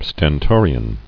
[sten·to·ri·an]